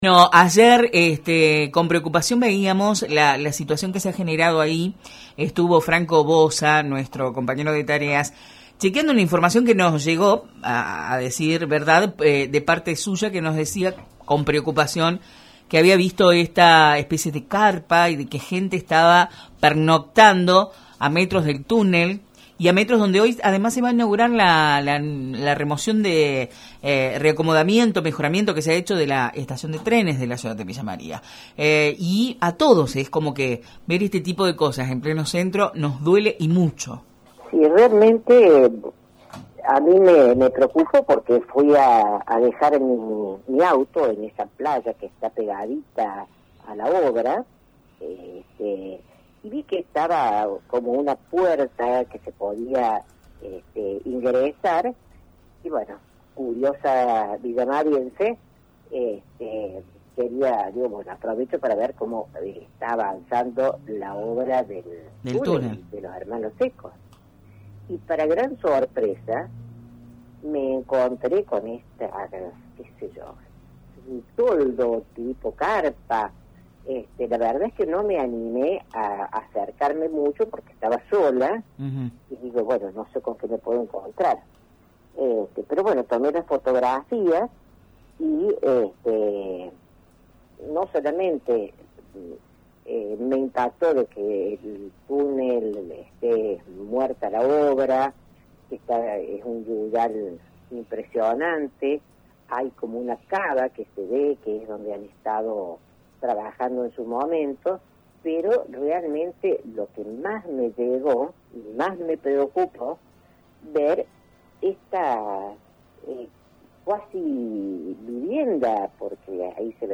con quien mantuvimos una charla telefónica.